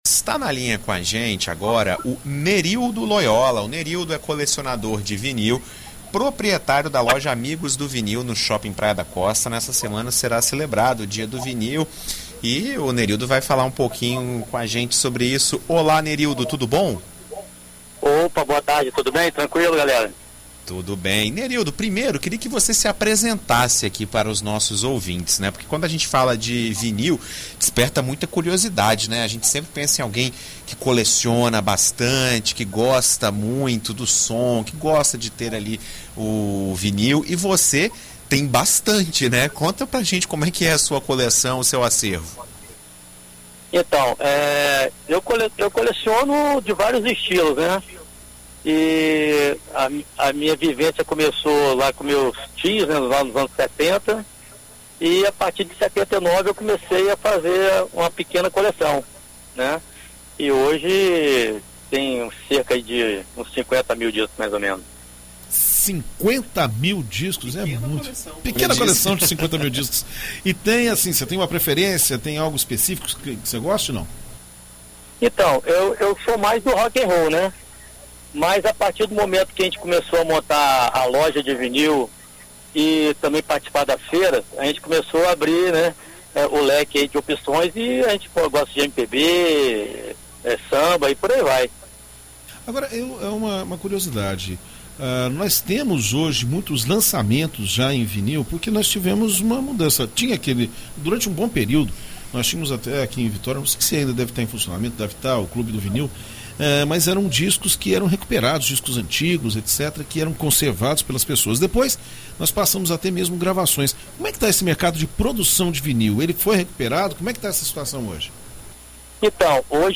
Em entrevista à BandNews FM ES nesta terça-feira